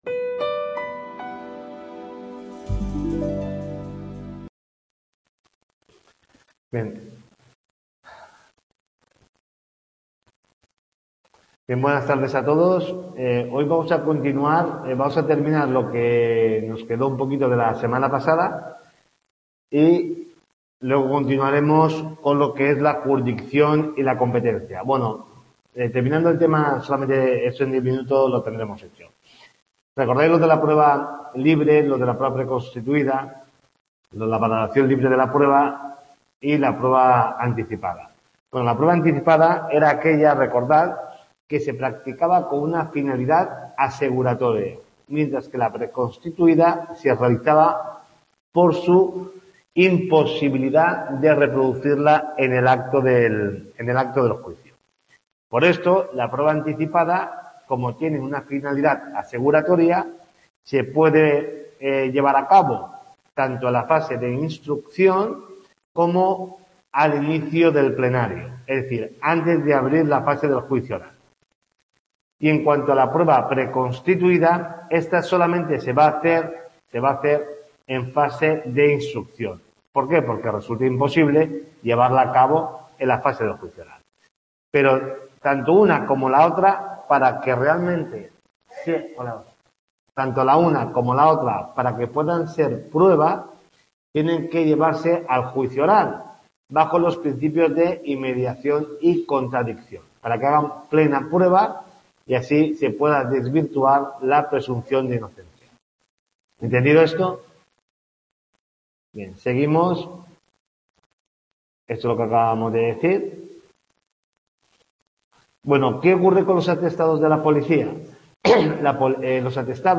TUTORIA 2